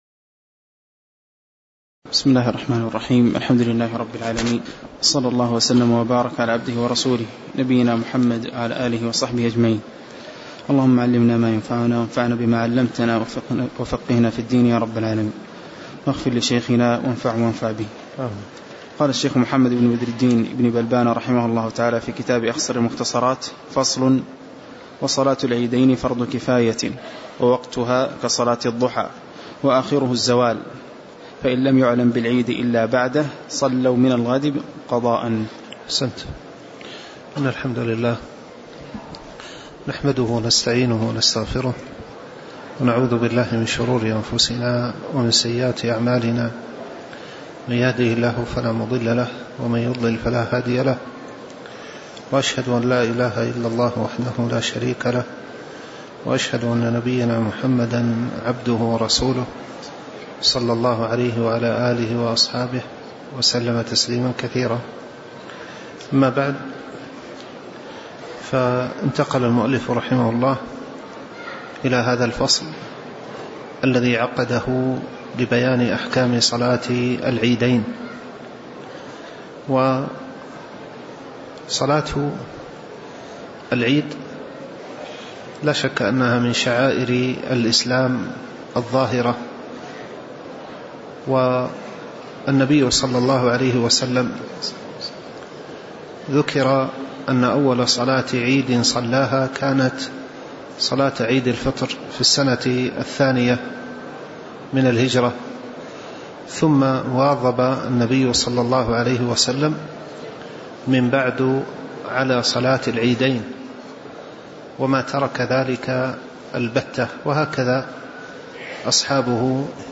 تاريخ النشر ٢٥ رجب ١٤٣٩ هـ المكان: المسجد النبوي الشيخ